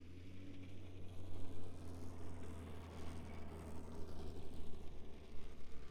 Zero Emission Snowmobile Description Form (PDF)
Zero Emission Subjective Noise Event Audio File - Run 1 (WAV)